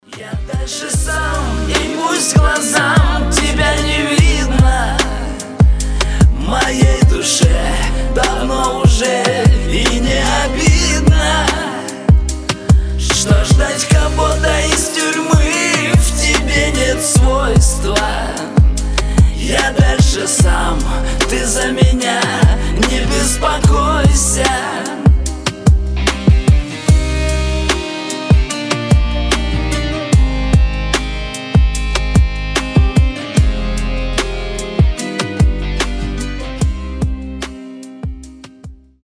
• Качество: 320, Stereo
грустные
тюремная лирика